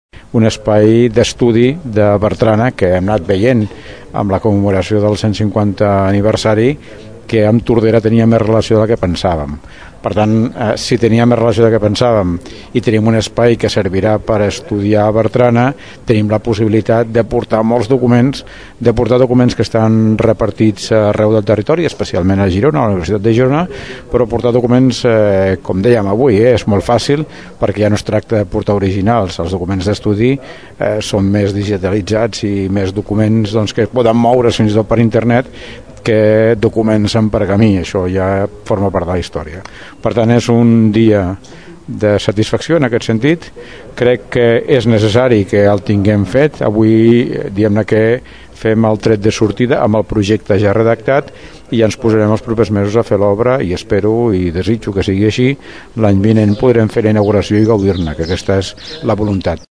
Ara, després d’uns quants anys, l’Arxiu Històric Local serà una realitat i acollirà el centre d’estudis i recerca sobre Bertrana. Joan Carles Garcia és l’Alcalde de Tordera.